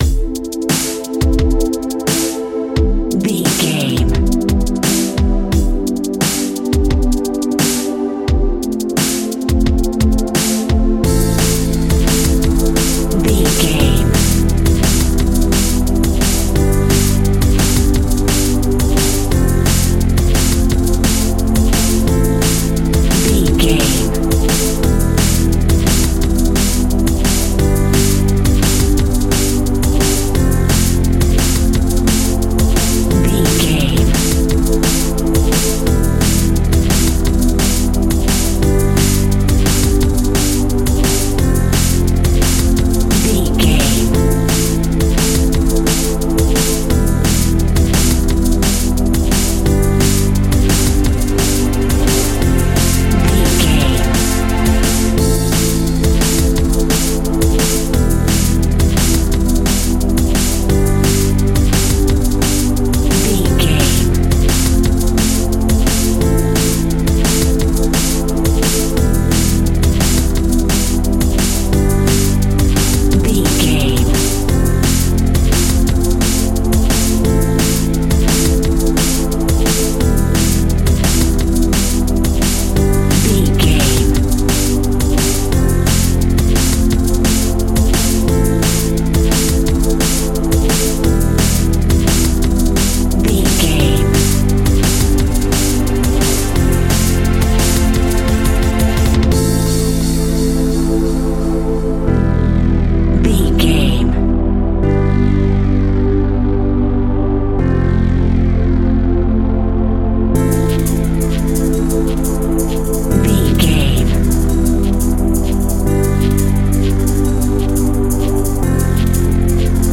Aeolian/Minor
Fast
futuristic
hypnotic
industrial
frantic
aggressive
synthesiser
drums
sub bass
synth leads